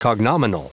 Prononciation du mot cognominal en anglais (fichier audio)
Prononciation du mot : cognominal